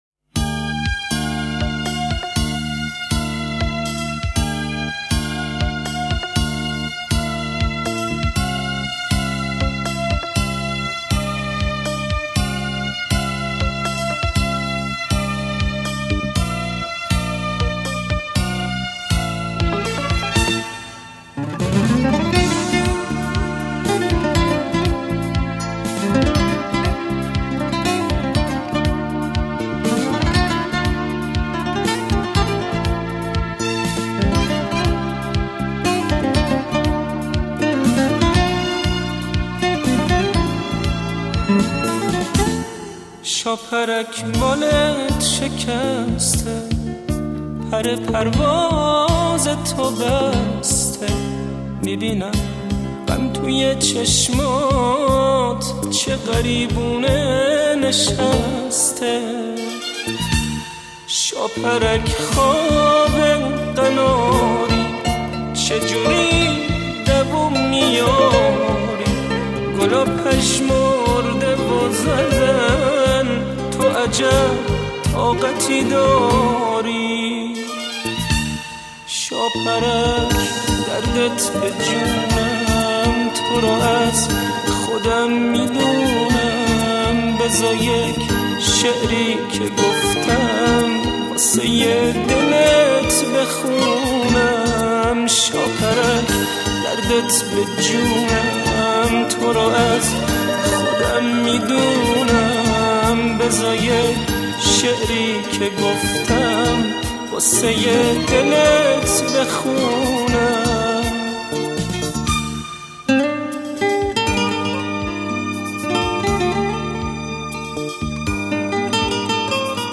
Мусиқа ва тарона Эрон мусиқаси